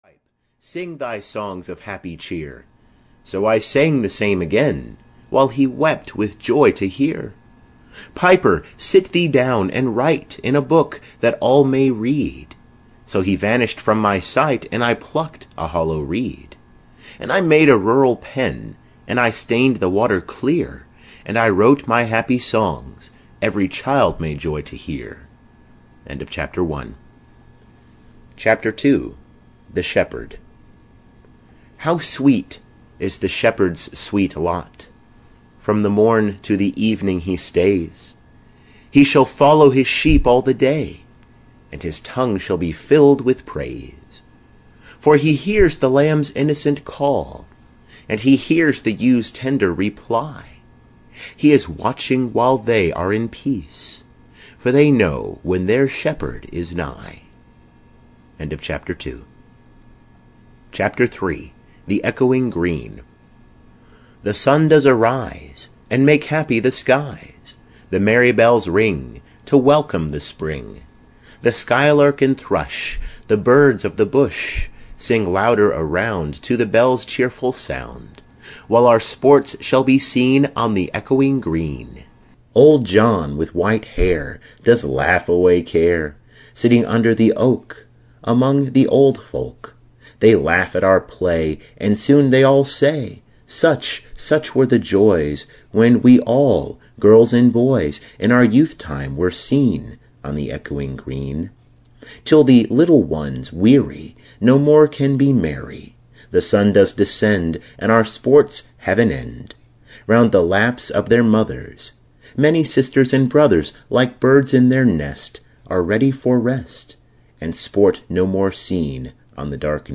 Poems of William Blake (EN) audiokniha
Ukázka z knihy